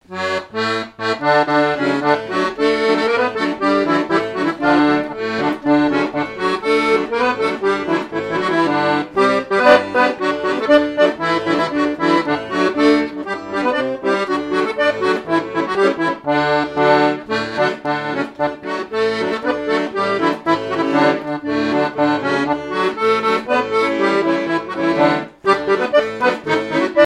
danse : polka
airs de danses issus de groupes folkloriques locaux
Pièce musicale inédite